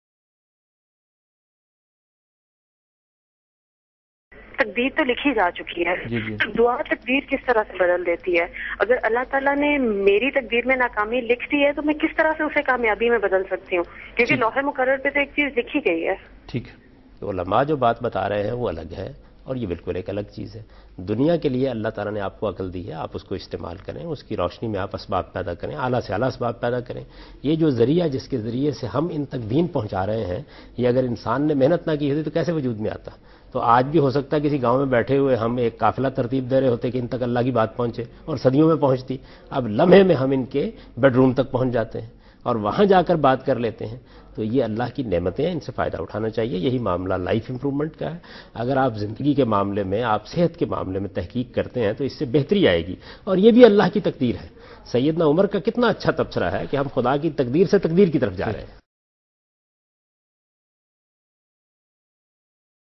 Javed Ahmad Ghamidi answers a question regarding "Prayers and Predetermination" in program Deen o Daanish on Dunya News.
جا وید احمد غامدی دنیا نیوز پر پروگرام دین و دانش میں "دعا اور تقدیر" کے بارے میں ایک سوال کا جواب دے رہے ہیں۔